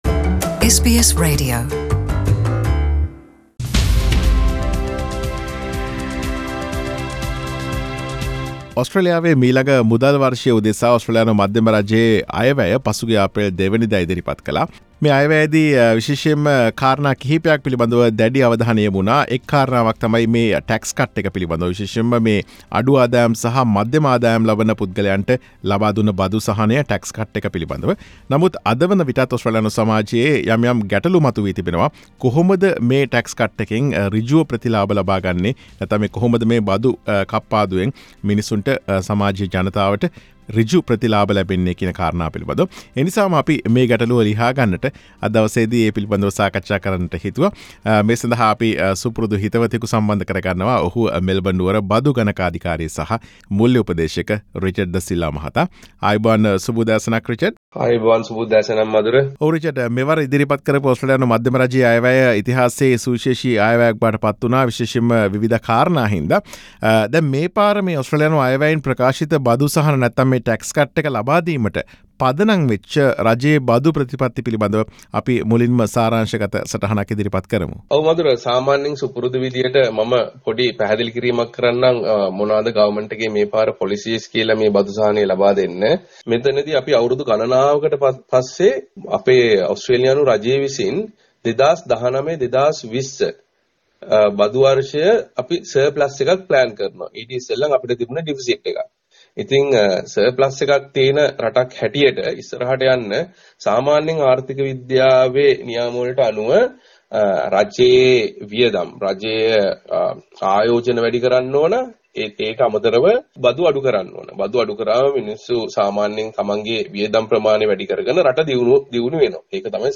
SBS සිංහල වැඩසටහන සිදු කළ සාකච්ඡාව.